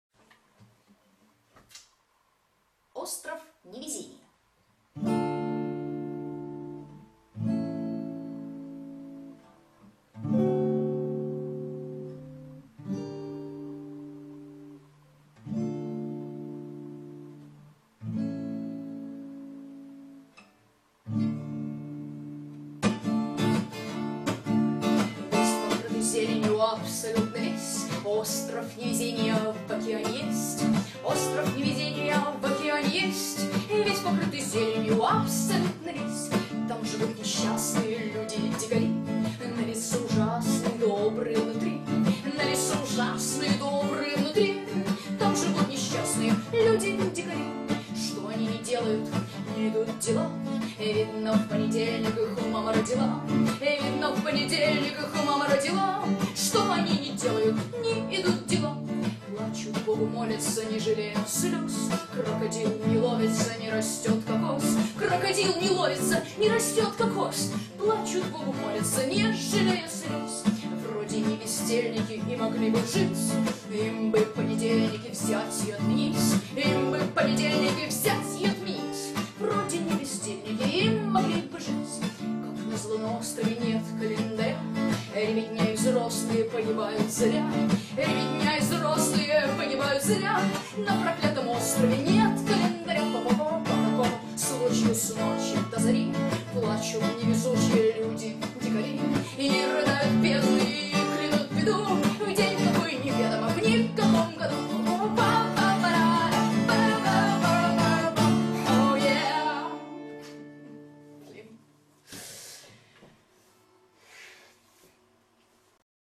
Russian songs